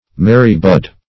mary-bud - definition of mary-bud - synonyms, pronunciation, spelling from Free Dictionary Search Result for " mary-bud" : The Collaborative International Dictionary of English v.0.48: Mary-bud \Ma"ry-bud`\, n. (Bot.)